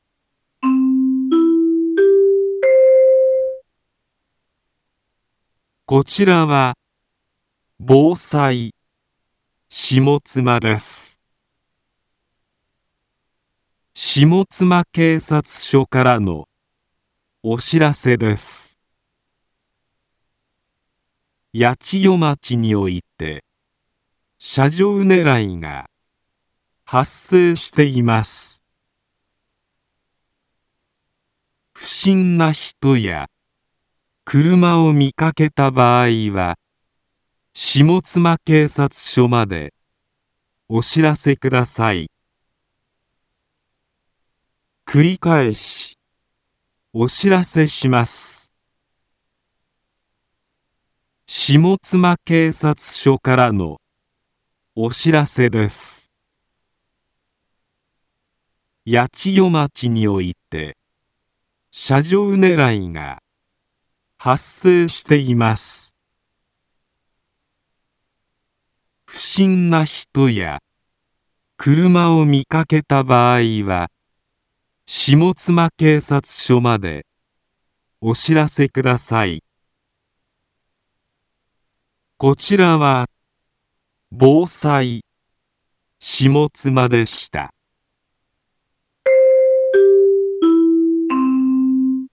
一般放送情報
Back Home 一般放送情報 音声放送 再生 一般放送情報 登録日時：2022-05-08 11:52:36 タイトル：車上ねらいへの警戒について インフォメーション：こちらは防災下妻です。